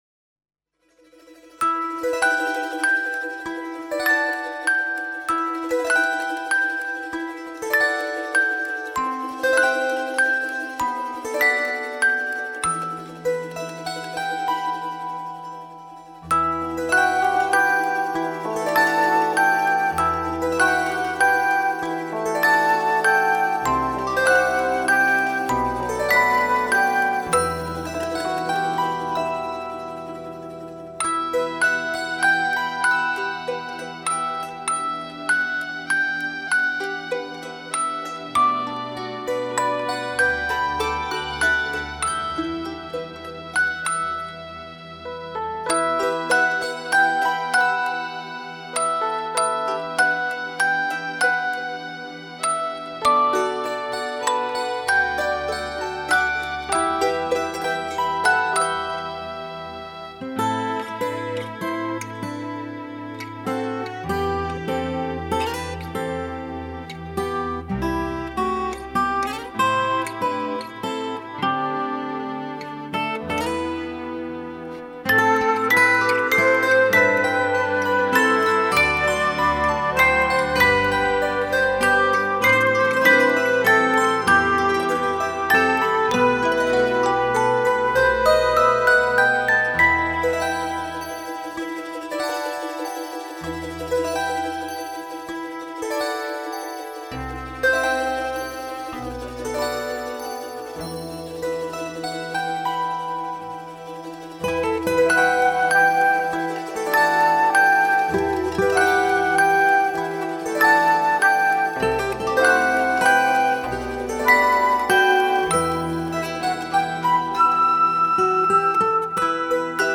классическая музыка
Очень нежная песенка😚😻
Очень милая колыбельная😚